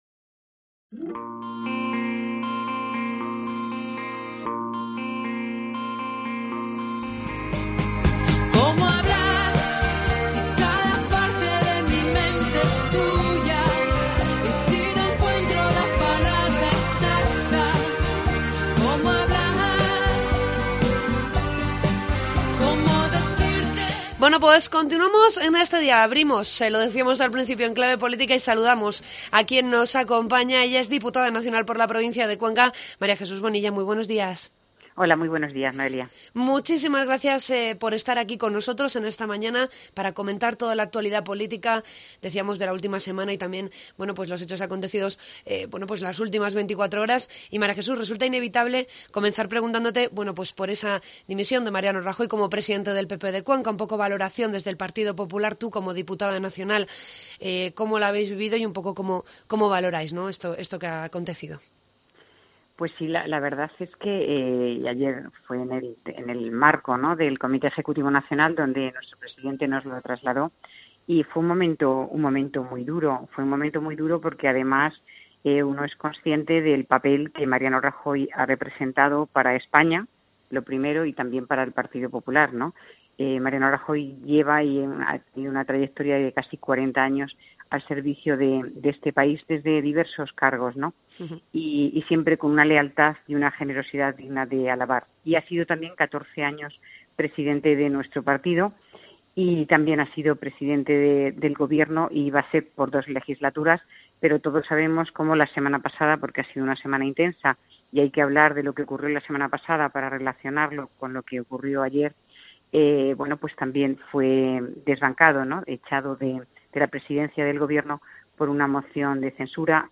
Entrevista a la diputada nacional del PP por la provincia de Cuenca, María Jesús Bonilla.